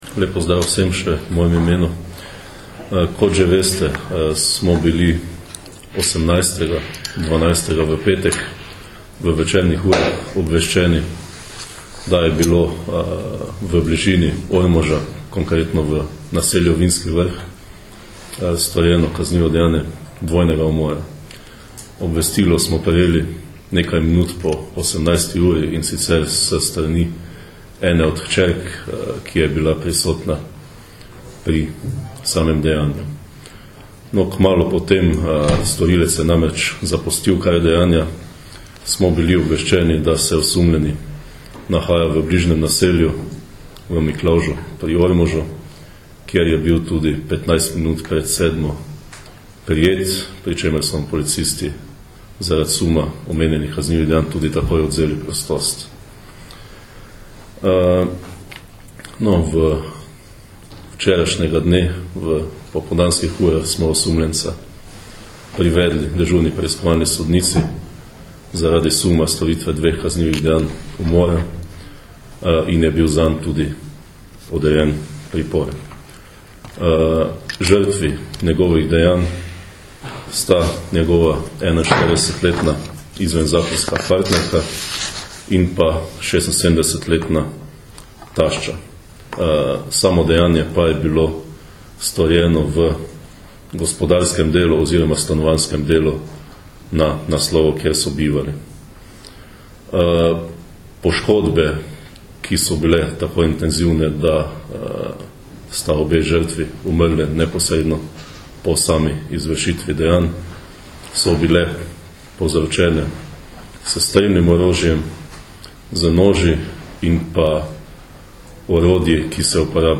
Policija - 46-letnik osumljen dveh kaznivih dejanj umora - informacija z novinarske konference PU Maribor